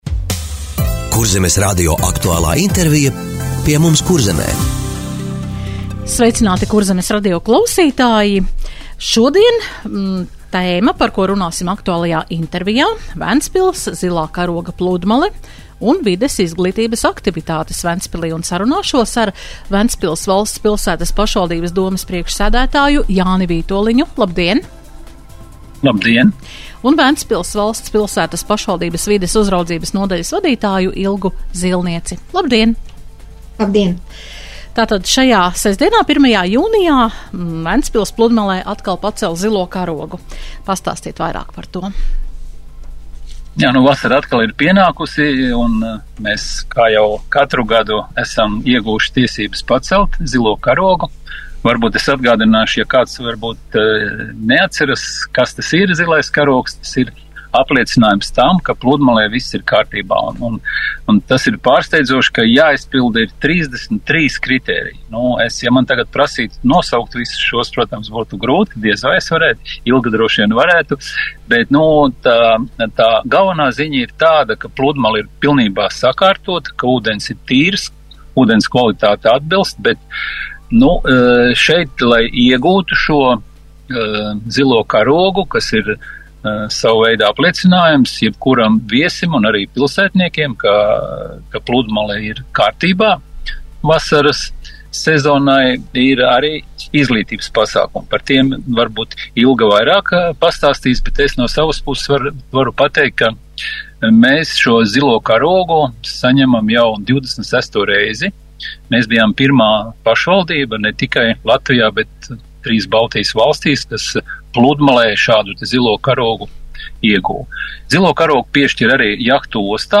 Radio saruna Ventspils Zilā karoga pludmale un vides izglītības aktivitātes - Ventspils